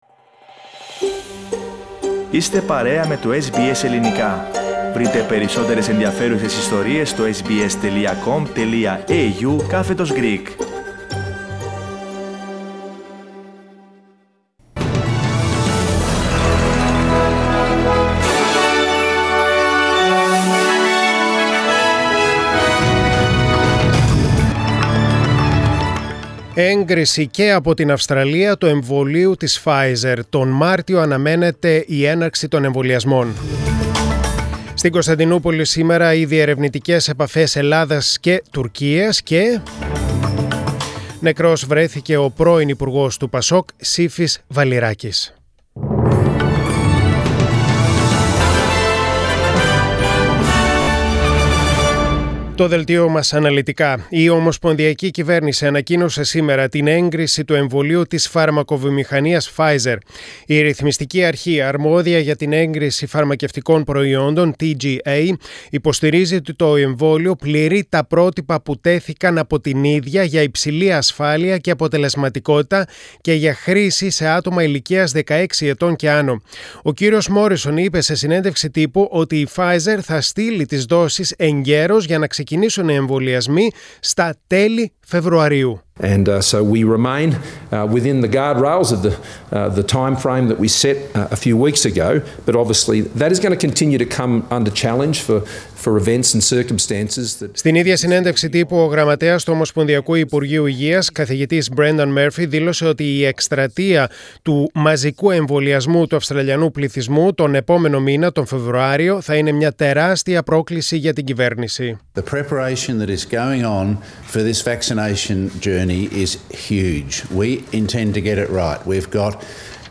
News in Greek: Monday 25.01.2021